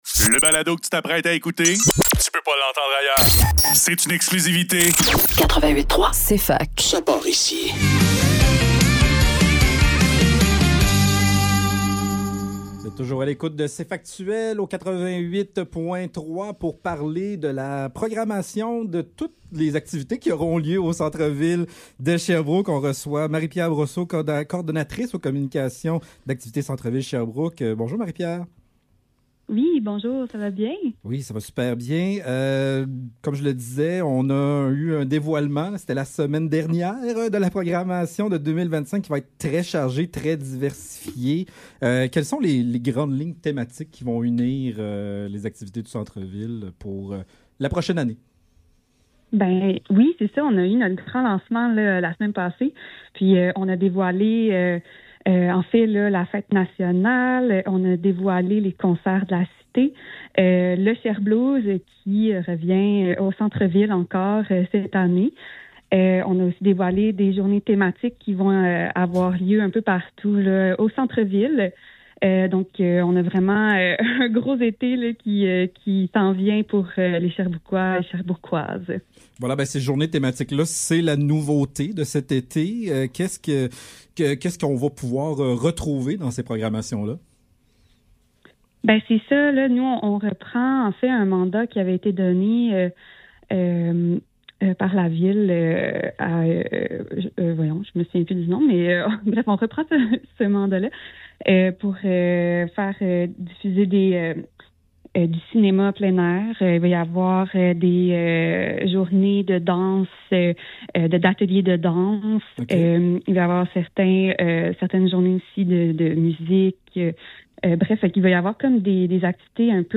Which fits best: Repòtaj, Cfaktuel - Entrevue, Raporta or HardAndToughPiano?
Cfaktuel - Entrevue